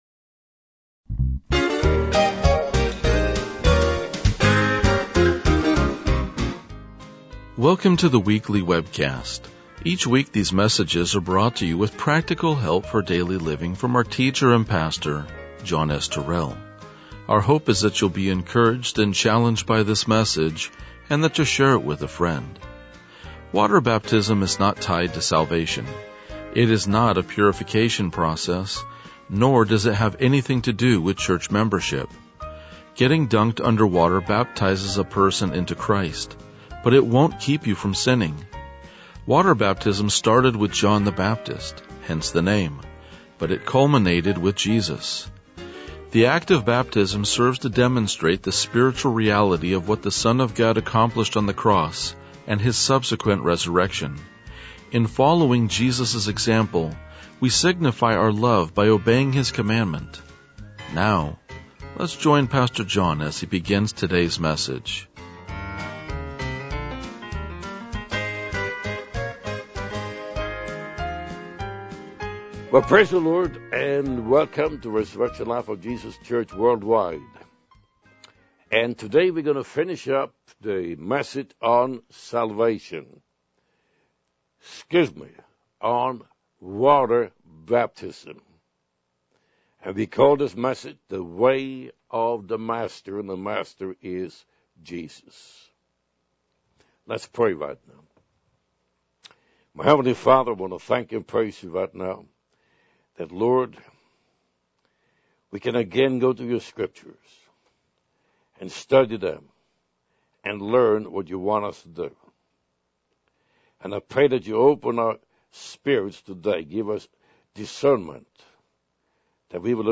RLJ-2015-Sermon.mp3